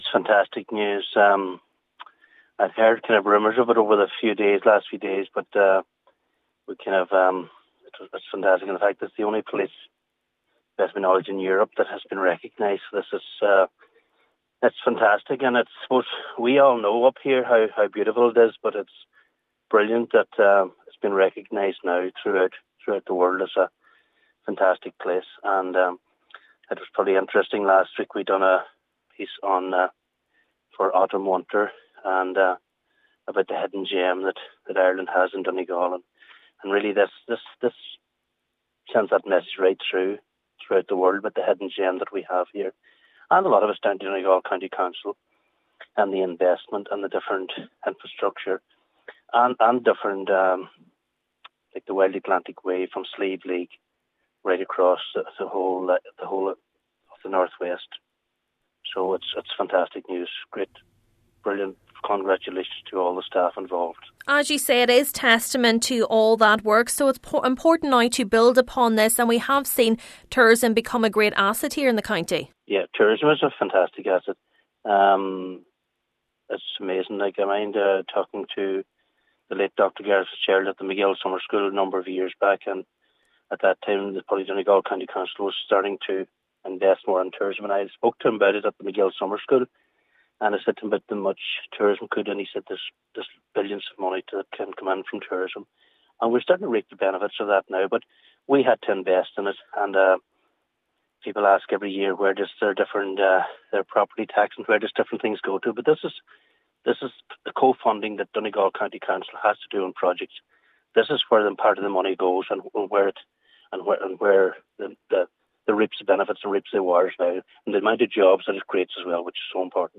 Councillor Martin Harley says the county truly is a hidden gem: